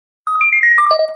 Dzwonki na telefon Kot
Kategorie Zwierzęta
Kot.mp3